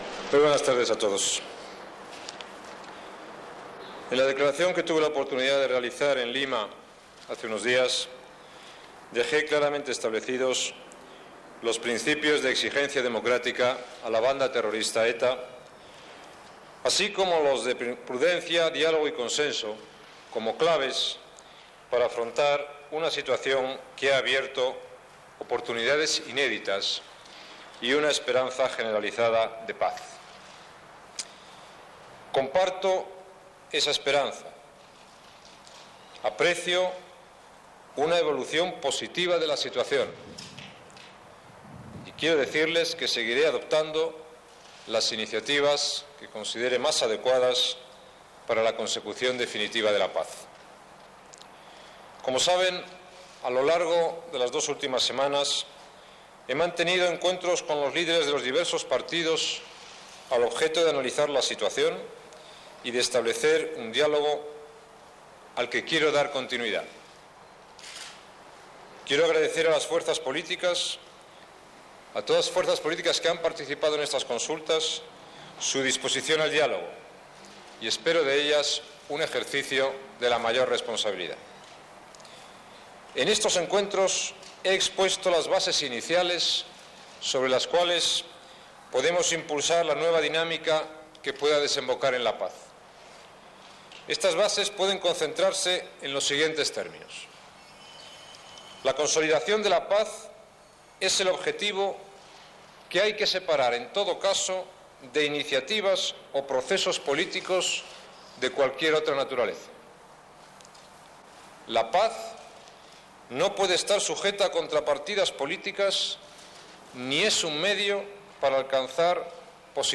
Intervenció al Congrés espanyol del president del govern José María Aznar sobre la treva de la banda terrorista ETA
Informatiu